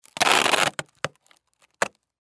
Звуки картонной коробки
Звук заклеивания коробки скотчем